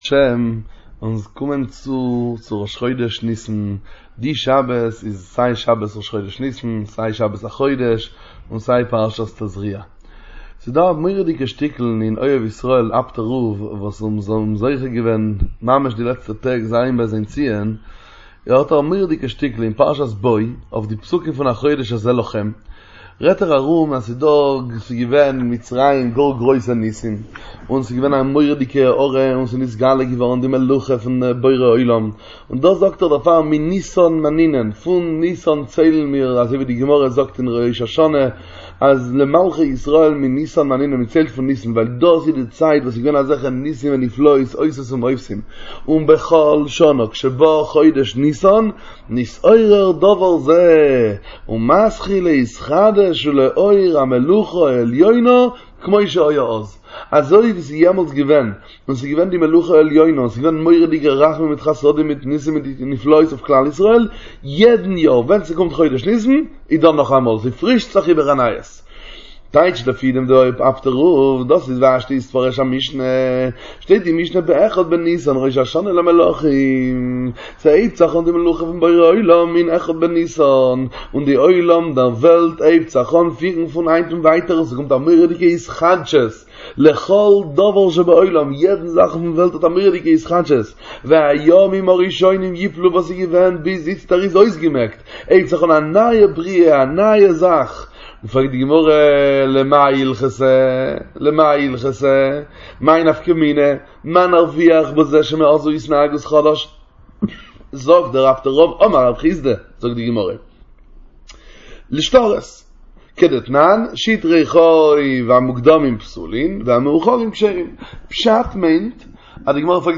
דבר תורה קצר מהבעל שם טוב הקדוש